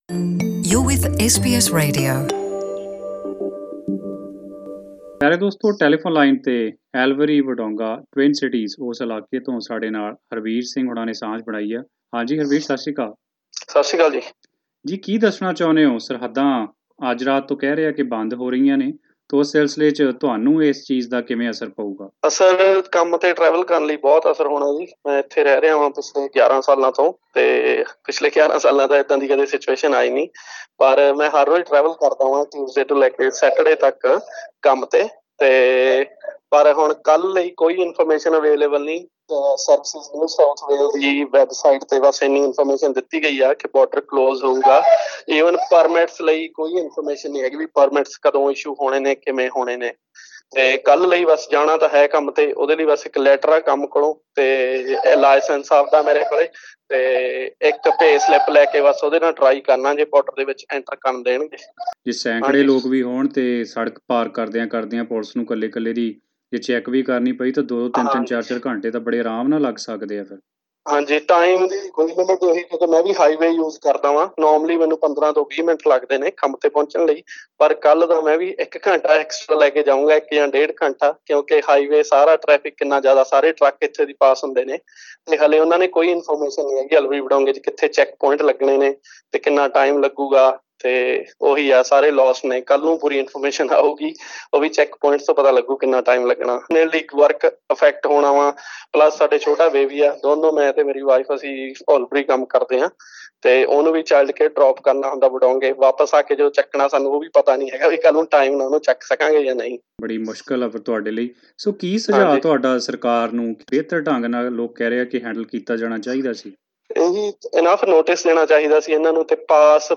ਇਸ ਸਬੰਧੀ ਪ੍ਰਤੀਕਿਰਿਆ ਲੈਣ ਲਈ ਅਸੀਂ ਓਥੇ ਵਸਦੇ ਪੰਜਾਬੀ ਭਾਈਚਾਰੇ ਦੇ ਲੋਕਾਂ ਨਾਲ਼ ਗੱਲਬਾਤ ਕੀਤੀ ਹੈ।